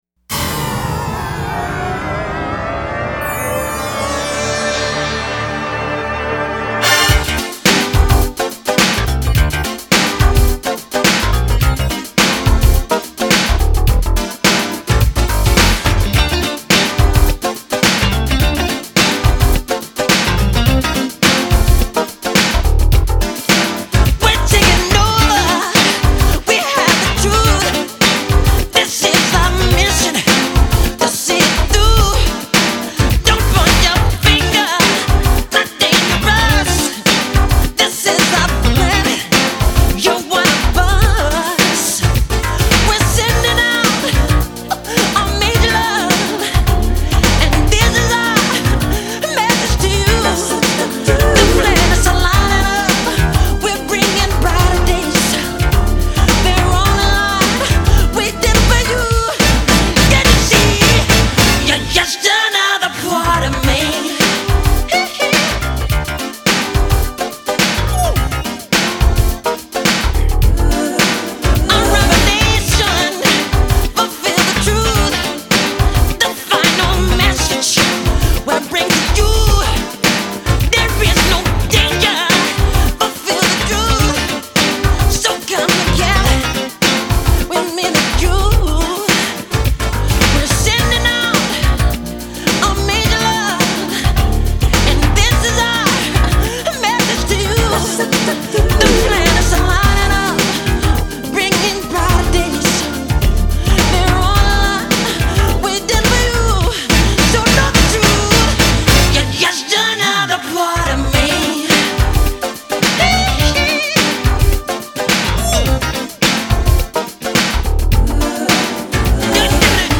Жанр: Pop-Rock, Soul Pop, downtempo, Funk